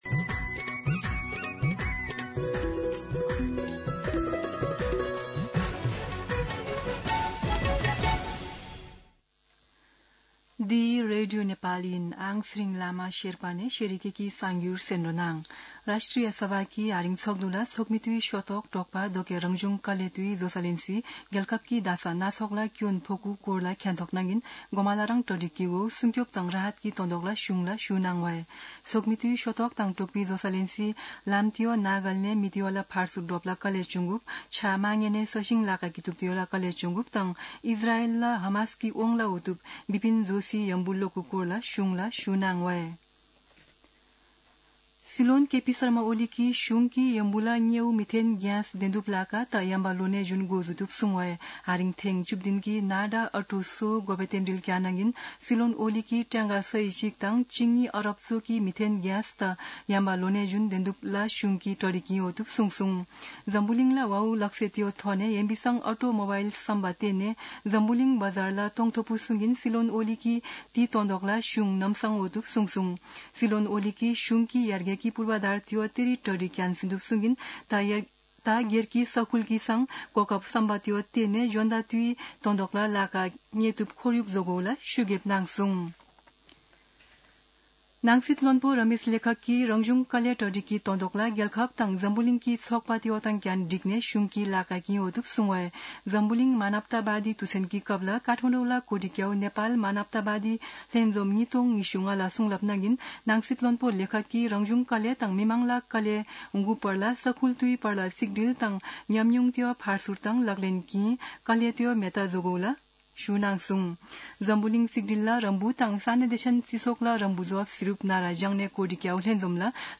शेर्पा भाषाको समाचार : ३ भदौ , २०८२
Sherpa-News-03.mp3